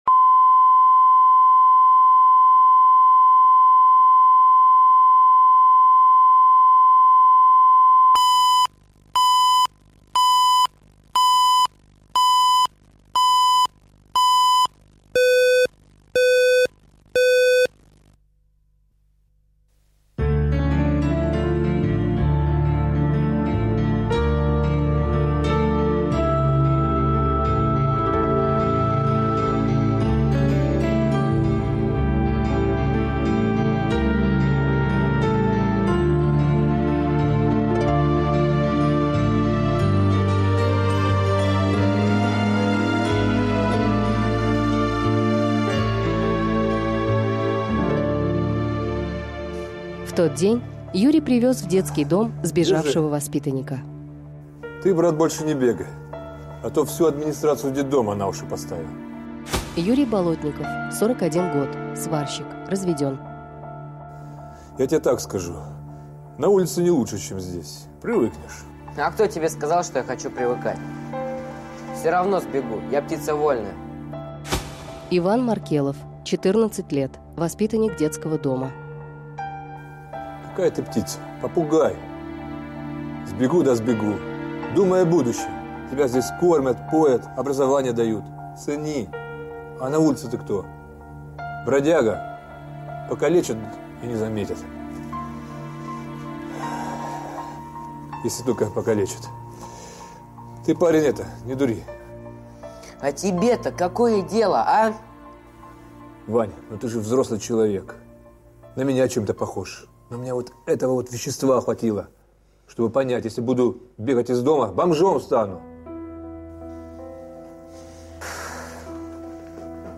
Аудиокнига Ничей ребенок | Библиотека аудиокниг